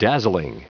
Prononciation du mot dazzling en anglais (fichier audio)
Prononciation du mot : dazzling